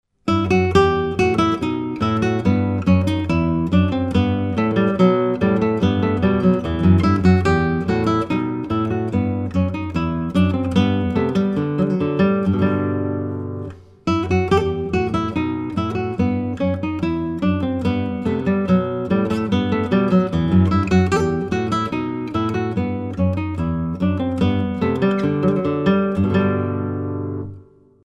Hauser 1937 style guitar
European Spruce soundboard, Indian Rosewood back & sides-